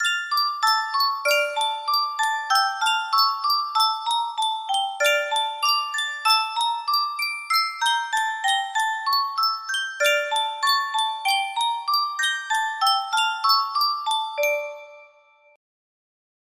Sankyo Music Box - Pablo de Sarasate Zigeunerweisen EC music box melody
Full range 60